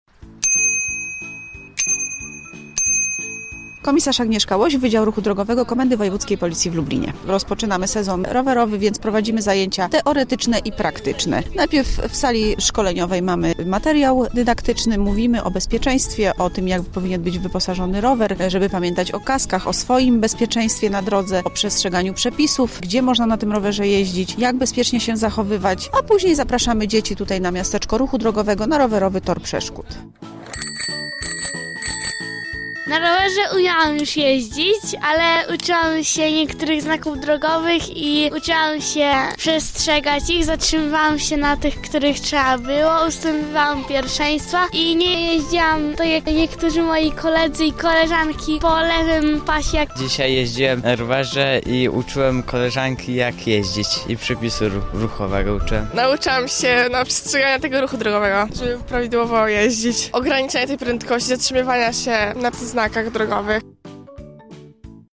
Relację z akcji przygotowała nasza reporterka: